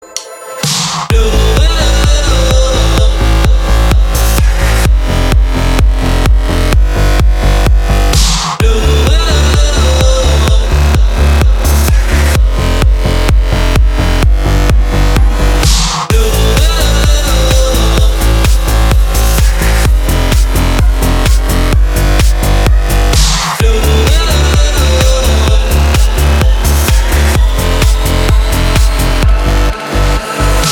• Качество: 320, Stereo
мужской голос
EDM
мощные басы
progressive trance
electro house